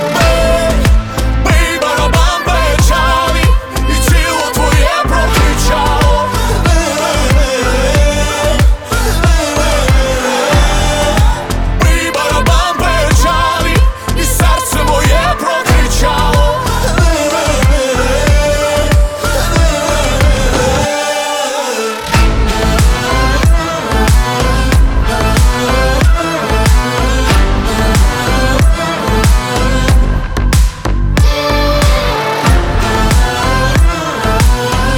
Жанр: Русская поп-музыка / Поп / Русский рок / Русские